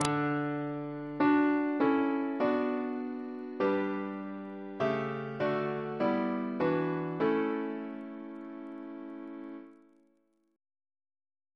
Single chant in D Composer: Peter Hurford (1930-2019) Reference psalters: ACP: 311; H1982: S42; RSCM: 187